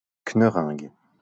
Knœringue (French pronunciation: [knœʁɛ̃ɡ]